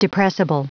Prononciation du mot depressible en anglais (fichier audio)